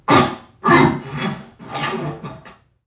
NPC_Creatures_Vocalisations_Robothead [39].wav